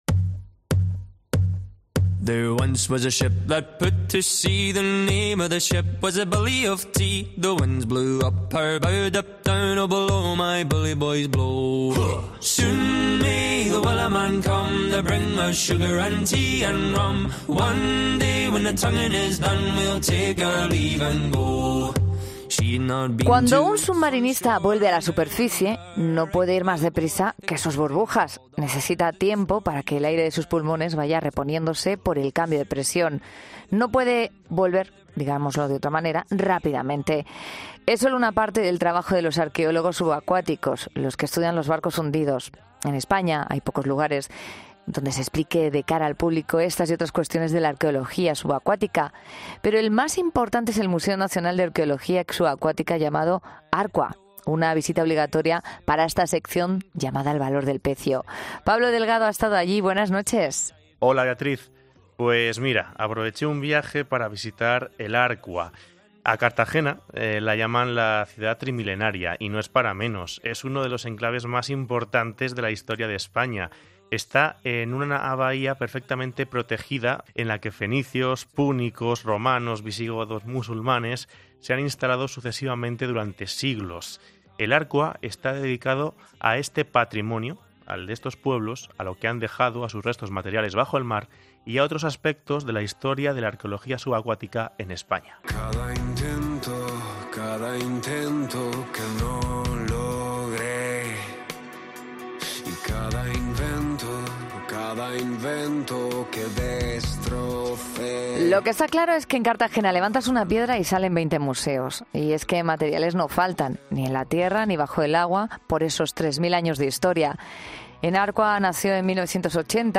Desde el ARQVA 2007 queda en el muelle Alfonso XII, a escasos metros del mar.